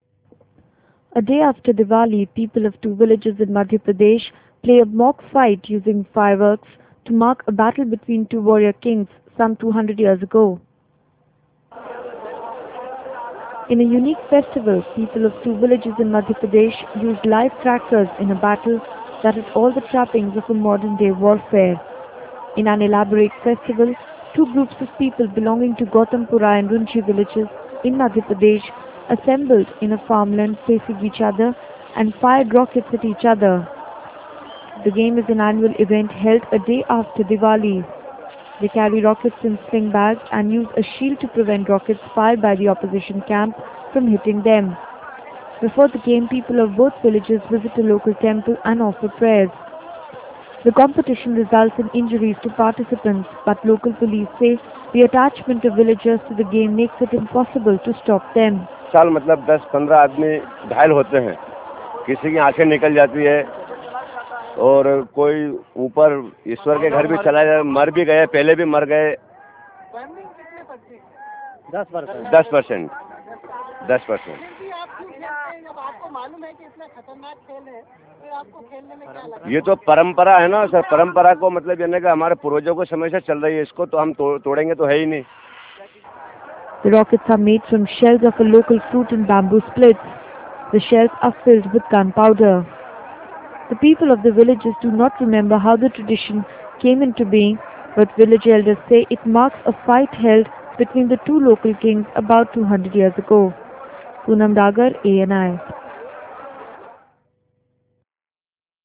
A day after Divali, people of two villages in Madhya Pradesh put up a mock fight using fireworks to mark a battle between two warrior kings some 200 years ago.